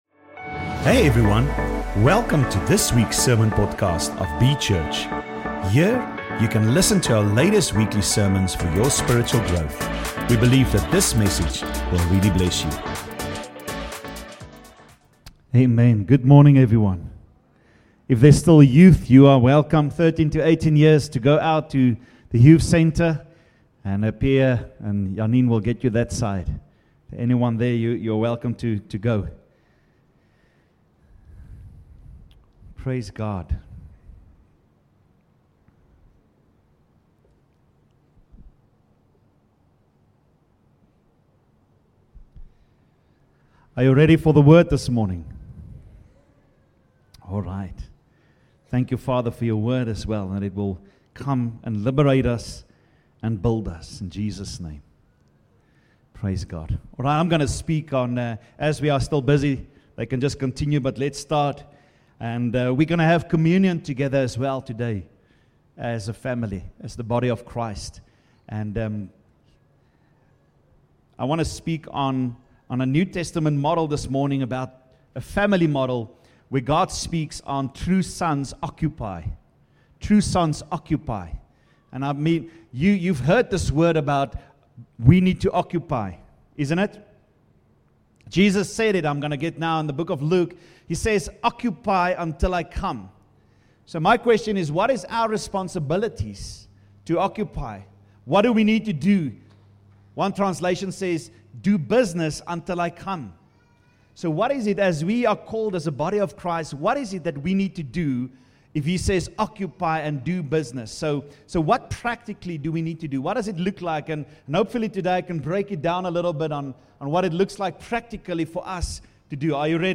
True Sons Occupy - Sunday Morning Service - 5 October 2025